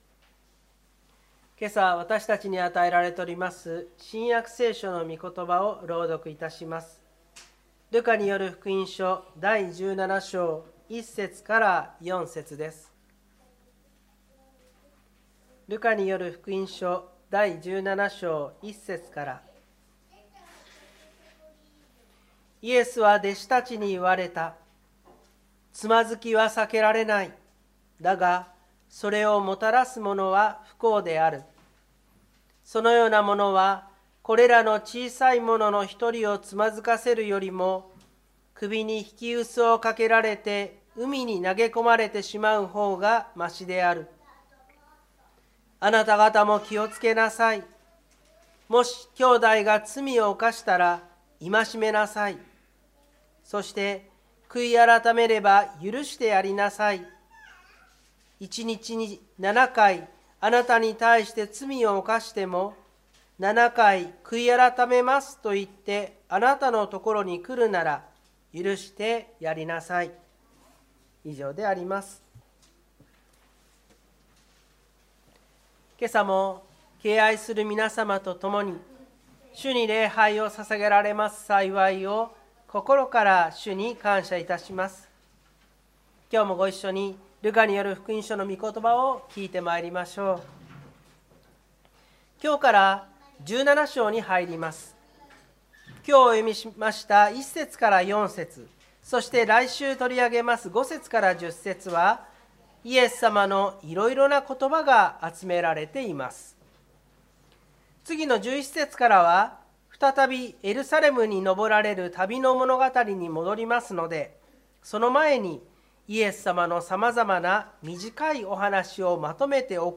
2025年02月16日朝の礼拝「もし兄弟が罪を犯したら」千葉県我孫子市のキリスト教会
湖北台教会の礼拝説教アーカイブ。